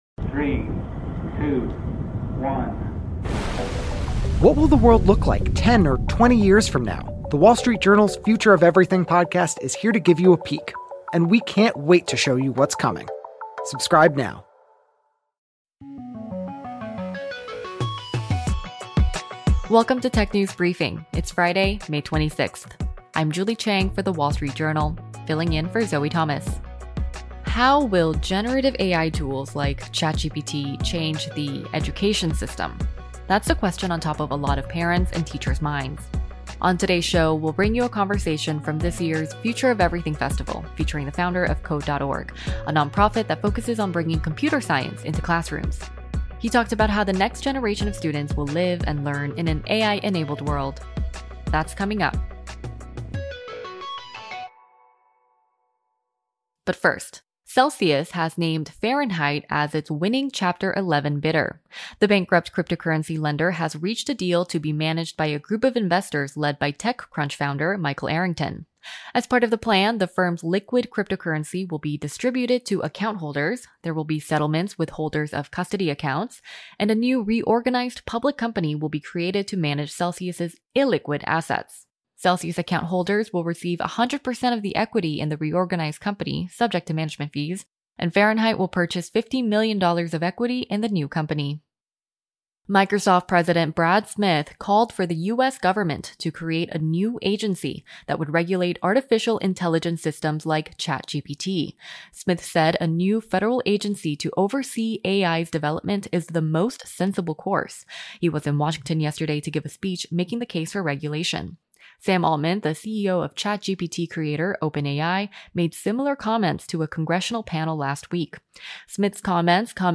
We bring you highlights from that conversation.